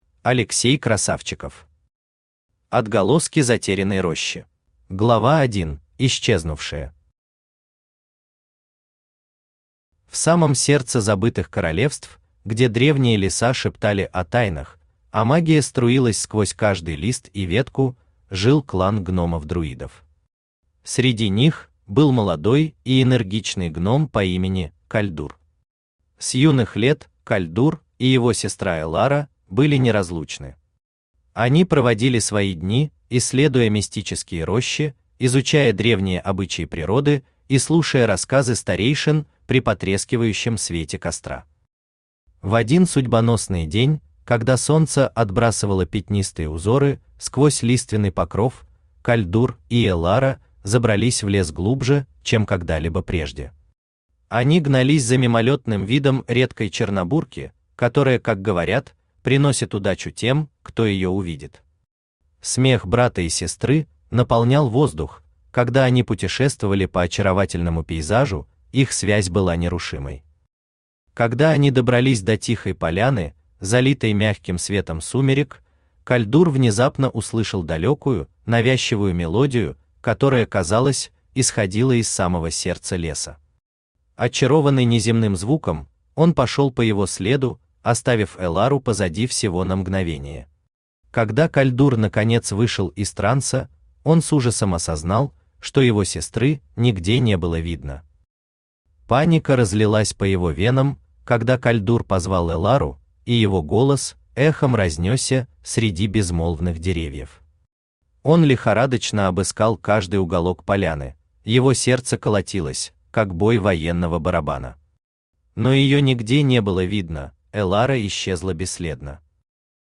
Аудиокнига Отголоски затерянной рощи | Библиотека аудиокниг
Aудиокнига Отголоски затерянной рощи Автор Алексей Михайлович Строгов Читает аудиокнигу Авточтец ЛитРес.